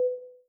error.wav